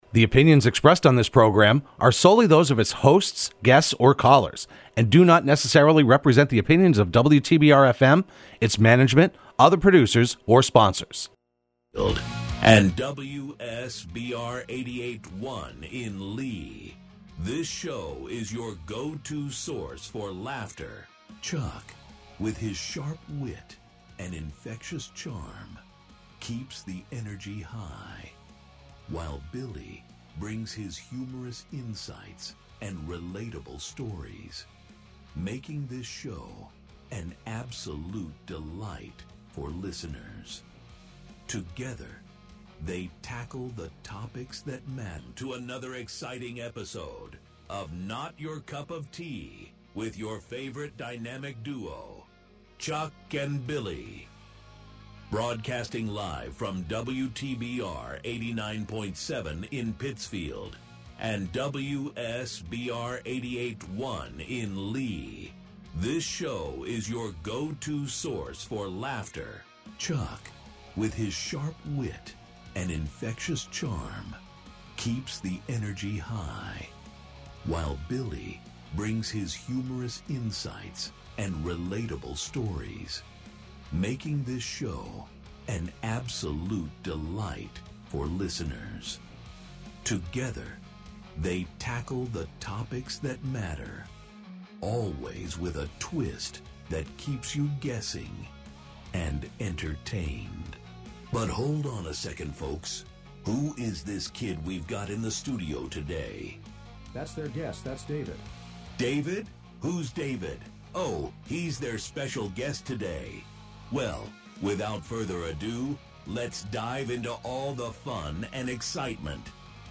Broadcast live every Wednesday afternoon at 4pm on WTBR.